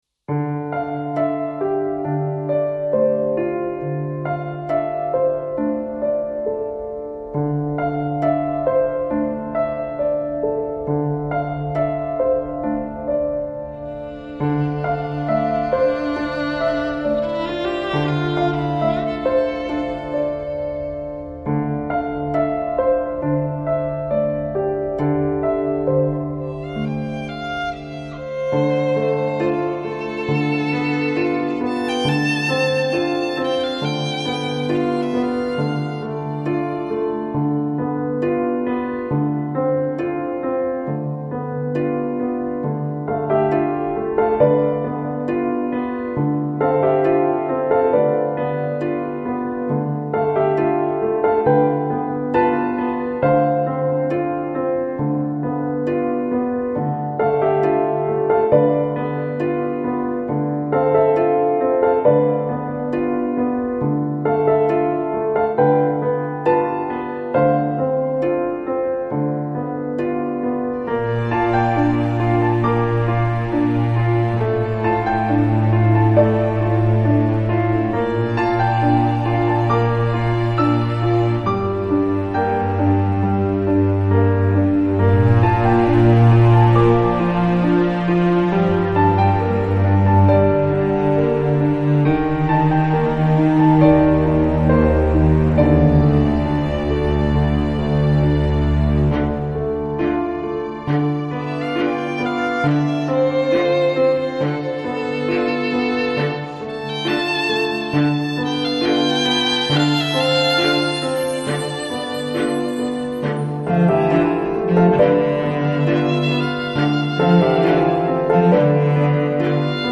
Жанр: Downtempo, Lounge, Chillout, Ambient